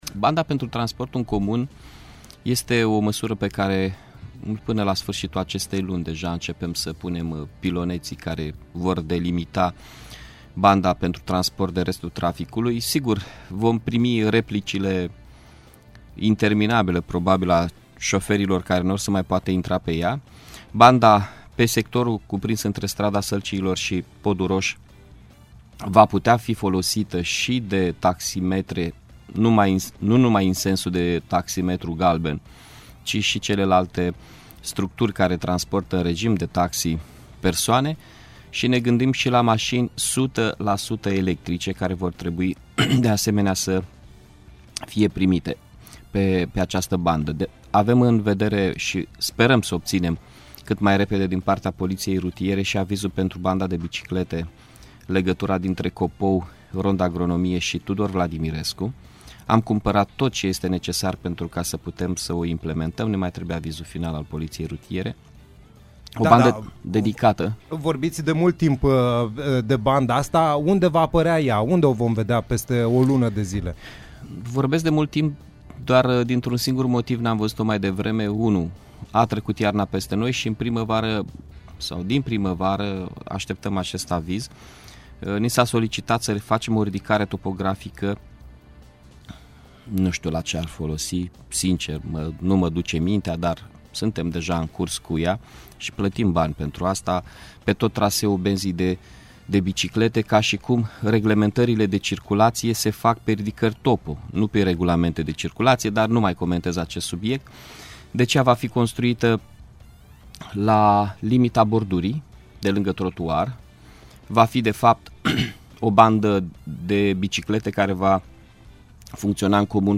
Invitat în emisiunea Play the Day, ediția de marți 22 iunie a fost primarul Iașului Mihai Chirica.